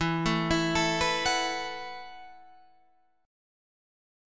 Esus2sus4 chord